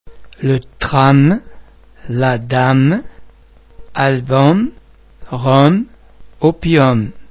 Please be mindful of the fact that all the French sounds are produced with greater facial, throat and other phonatory muscle tension than any English sound.
The French [m] and [mm] are normally pronounced a single [m] sound, as in the English words mother, famous, farm etc.